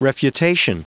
Prononciation du mot refutation en anglais (fichier audio)
Prononciation du mot : refutation